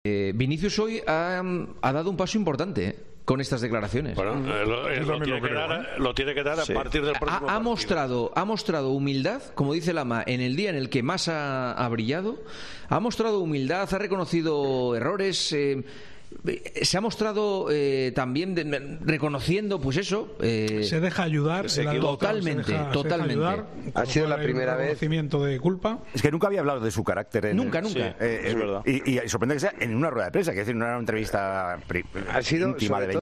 Fue el mejor sobre el terreno de juego, pero sus palabras marcaron el postpartido y en El Tertulión de Tiempo de Juego valoraron su Supercopa de España